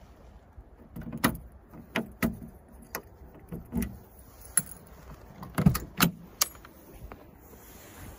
1. Замок двери Волги открыли ключом
gaz24-zamok-dveri.mp3